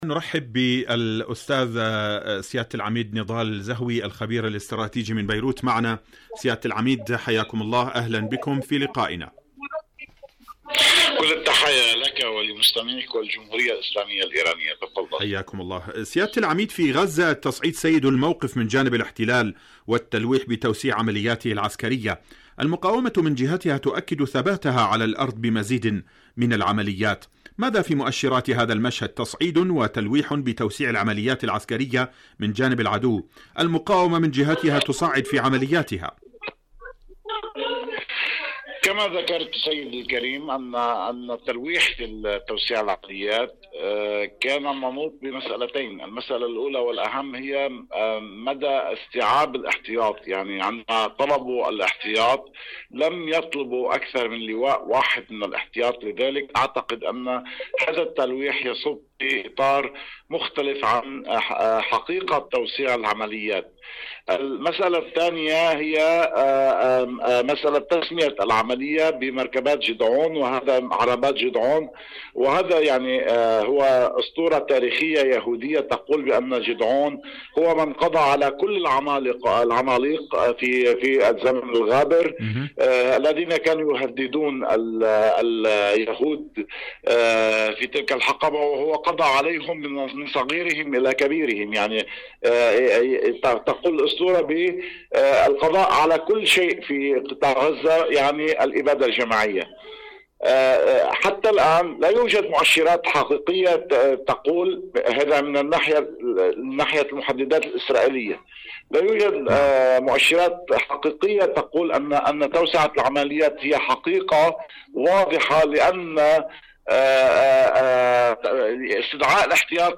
غزة تحسم المعادلة.. مقابلة